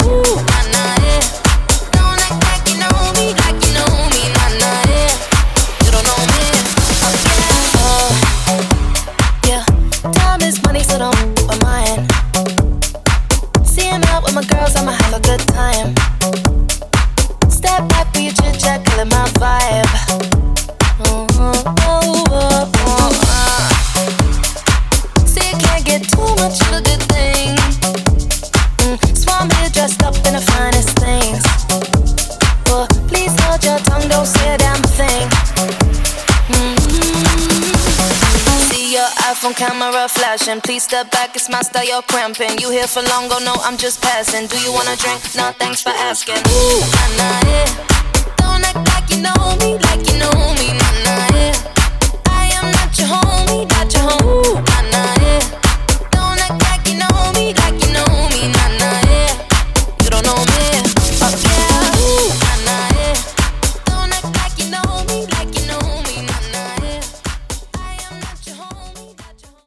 BOOTLEG , RE-DRUM , TOP40